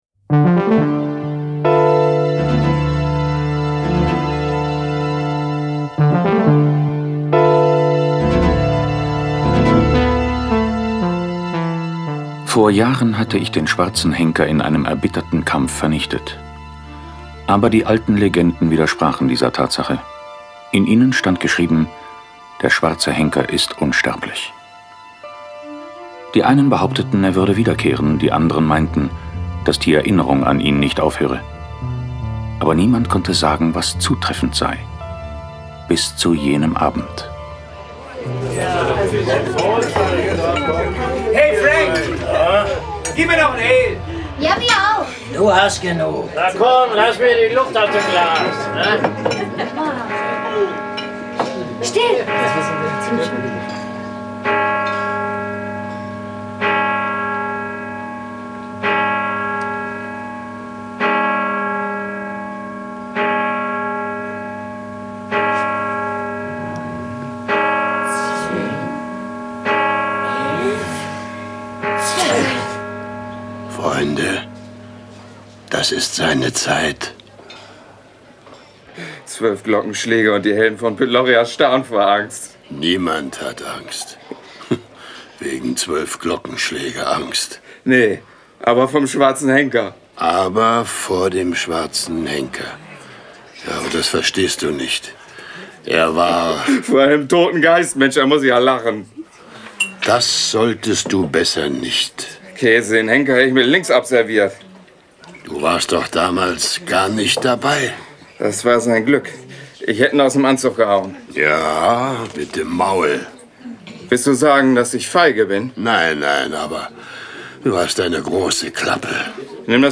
John Sinclair Tonstudio Braun - Folge 49 Der lächelnde Henker. Jason Dark (Autor) diverse (Sprecher) Audio-CD 2016 | 1.